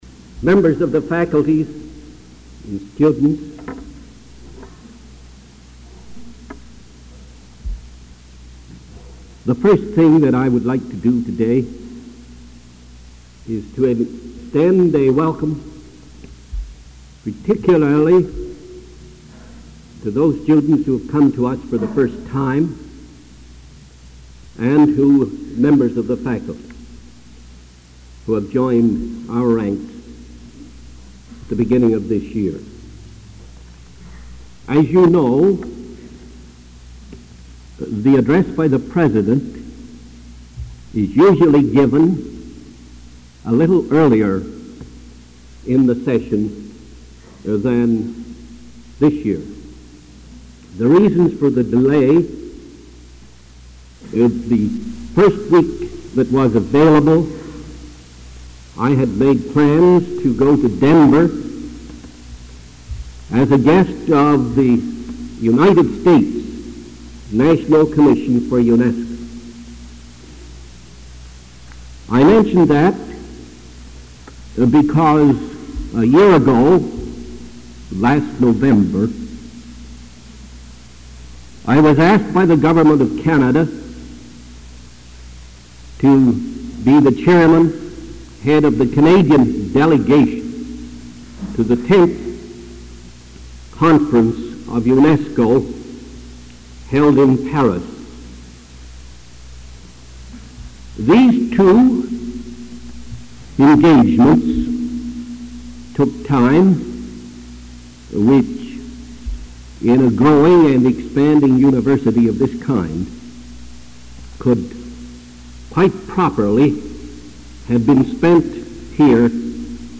[Address to students by President Norman A.M. MacKenzie]